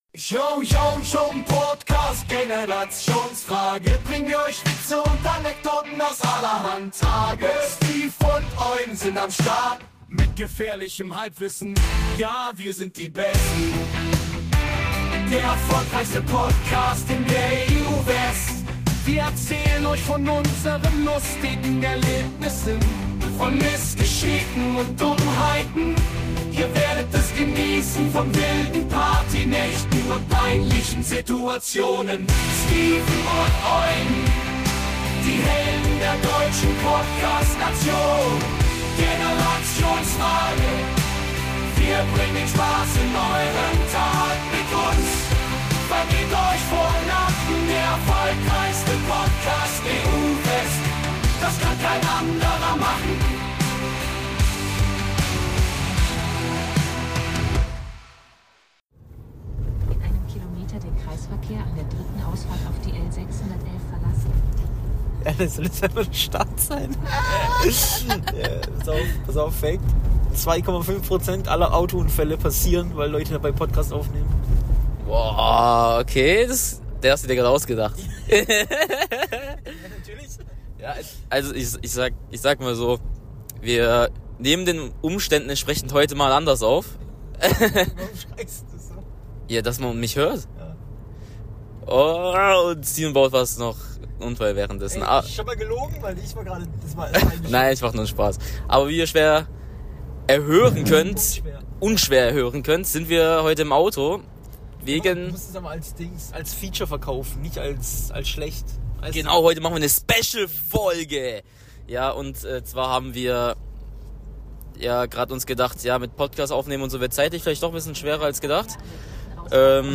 Diese Folge entstand unter enormen Zeitduck. Verzeiht die Tonqualität und das es evtl. etwas chaotisch ist (chaotischer als sonst) Wir fahren durch den Regen und reden über dieses und jenes, steigt einfach ein und fahrt mit.